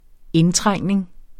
Udtale [ -ˌtʁaŋˀneŋ ]